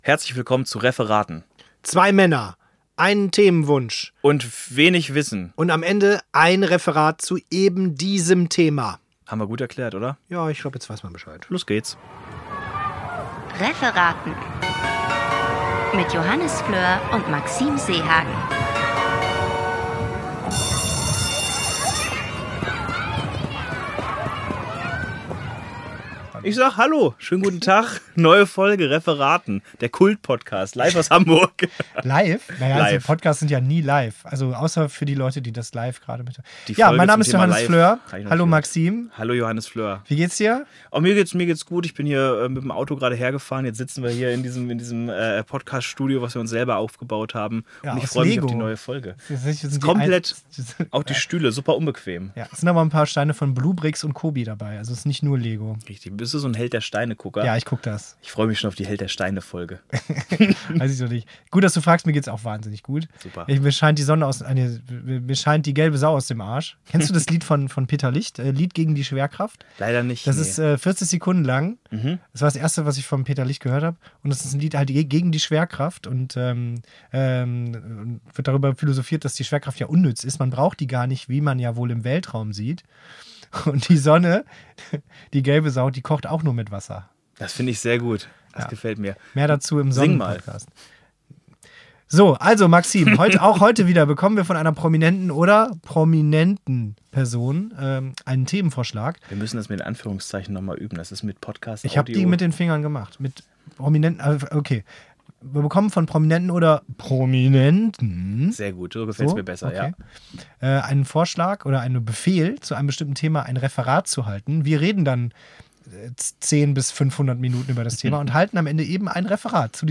Lauscht den beiden „Lümmeln“, wie sie den Live-Test machen: Wie ist es, eine Strumpfhose anzuziehen und zu tragen?